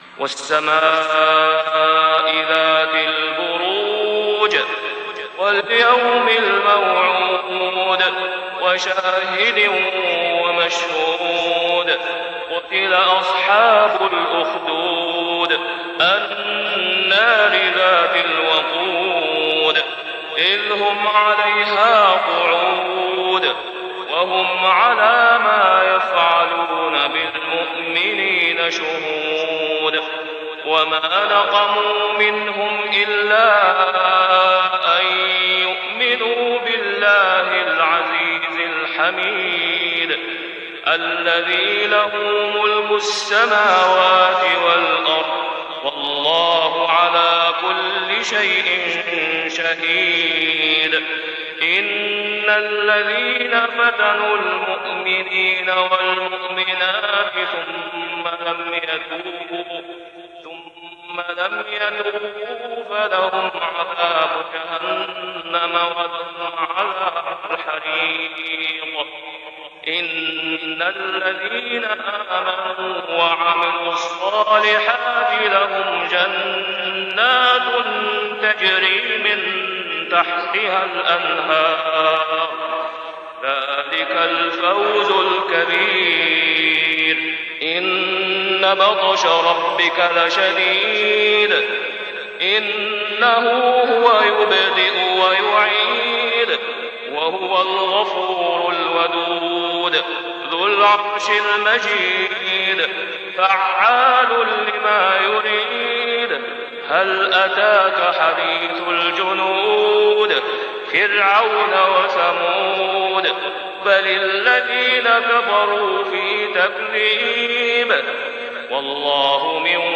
سورة البروج > السور المكتملة للشيخ أسامة خياط من الحرم المكي 🕋 > السور المكتملة 🕋 > المزيد - تلاوات الحرمين